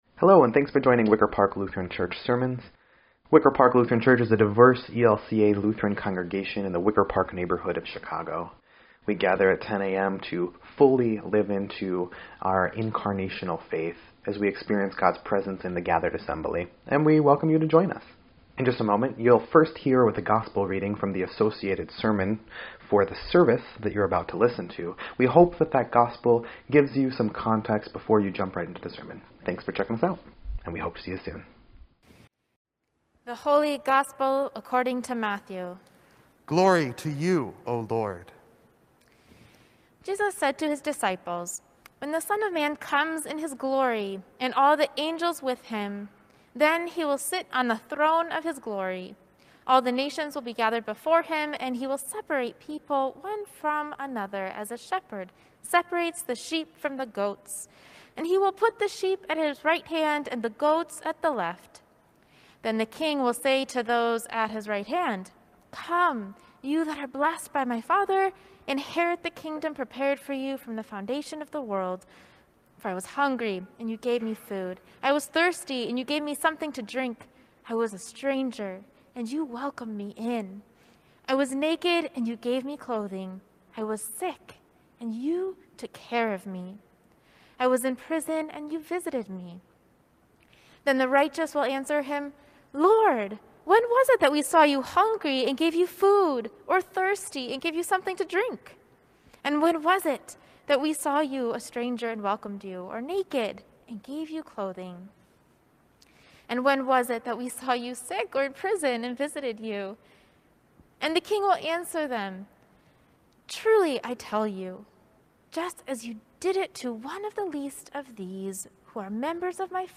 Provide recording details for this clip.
Third Sunday of Advent